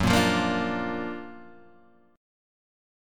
F#+M7 chord